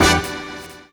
FeelSnakeBrass3.wav